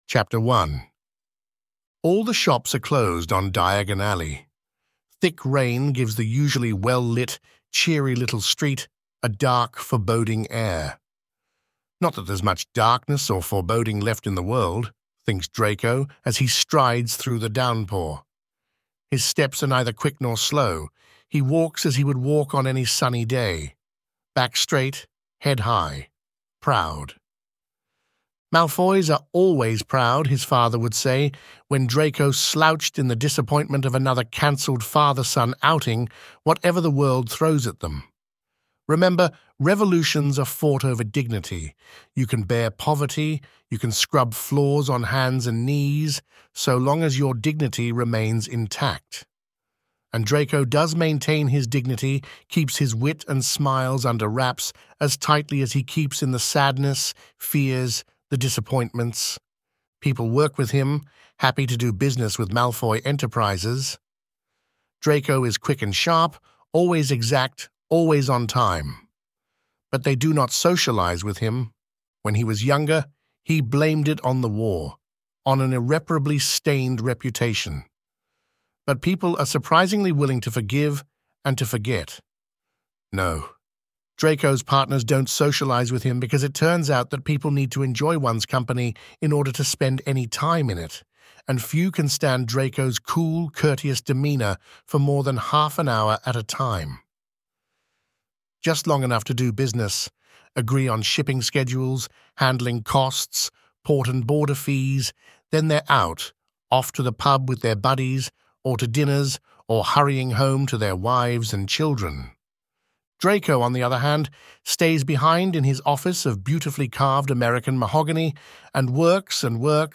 Out of the Dark, Chapter 1. Drarry Podfic